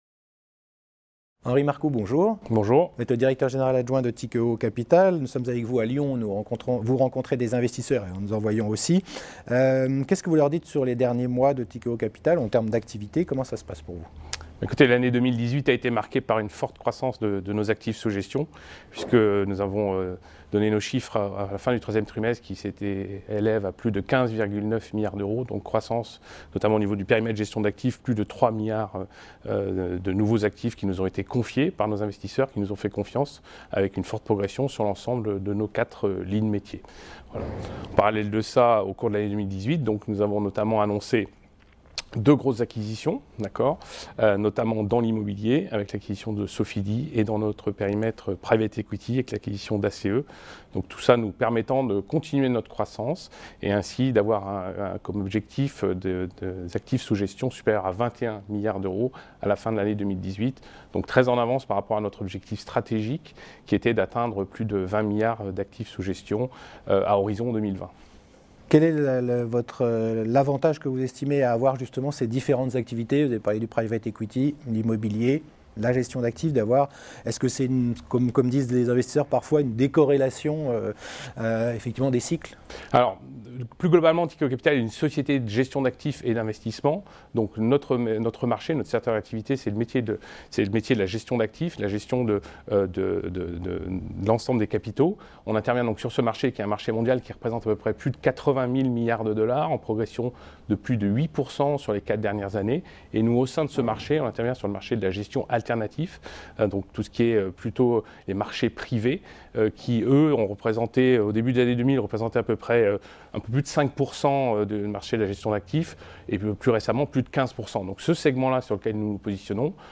La Web Tv a rencontré les dirigeants au Oddo Forum qui s'est tenu à Lyon le 10 et le 11 janvier